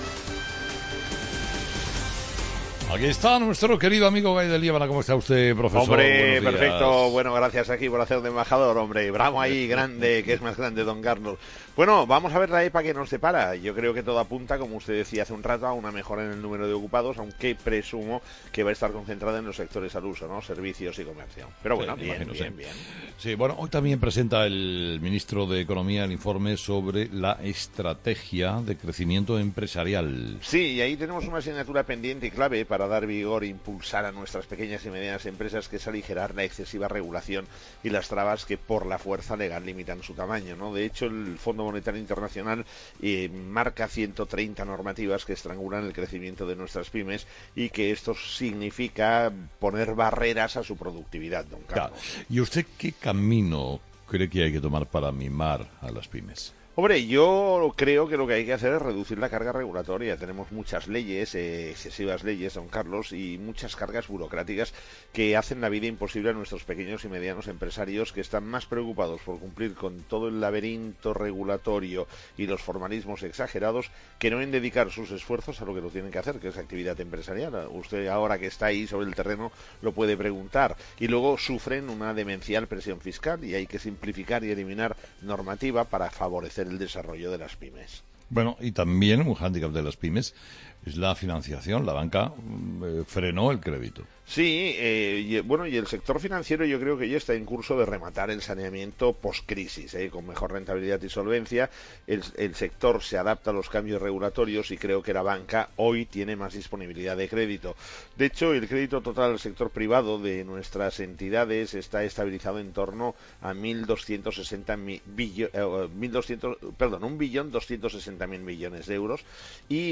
Todas las mañanas la actualidad económica en 'Herrera en COPE' con el profesor Gay de Liébana.